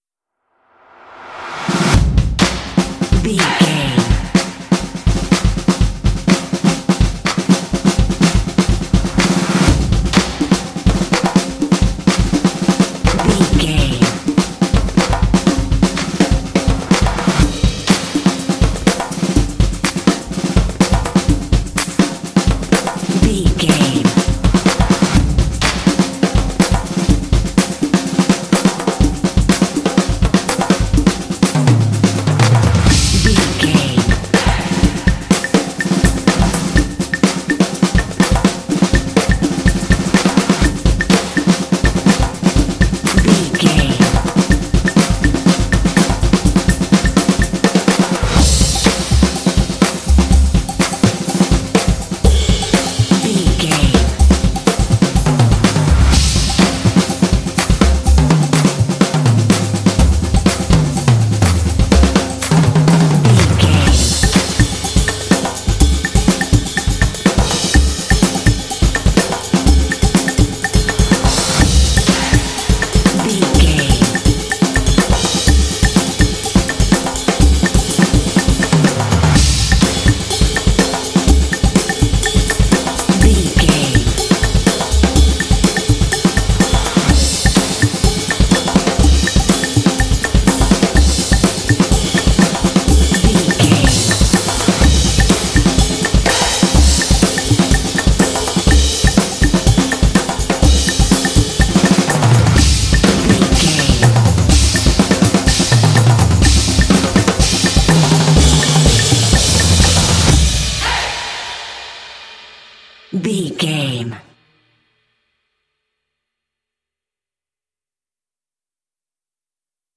This epic drumline will pump you up for some intense action.
Epic / Action
Atonal
driving
motivational
determined
epic
vocals
drumline